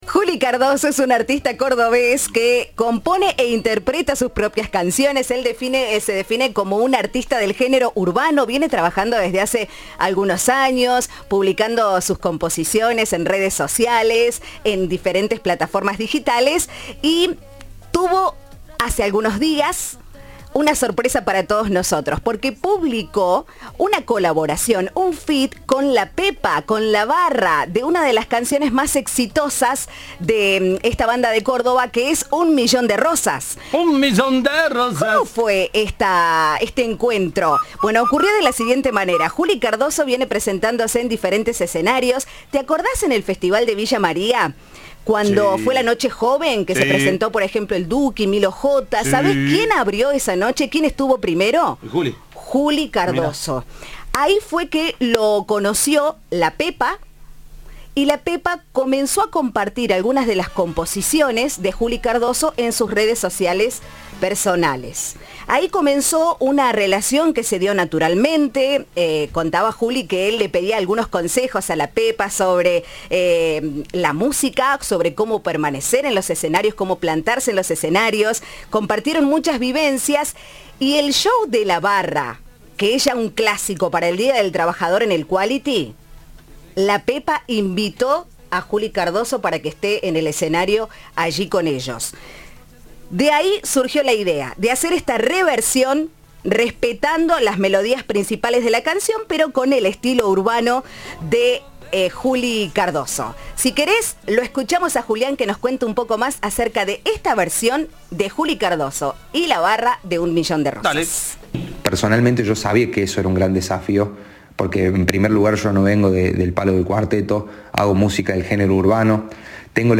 En diálogo con Cadena 3, el joven reconoció que el desafío era significativo, ya que su música no proviene del cuarteto, pero se mostró entusiasmado por la oportunidad de mezclar géneros.